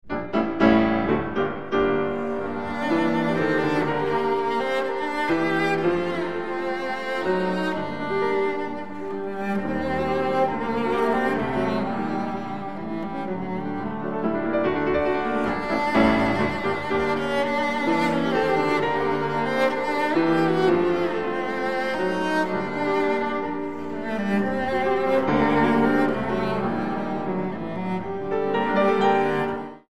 Cello
Piano
Cello Sonata in G minor, Op.19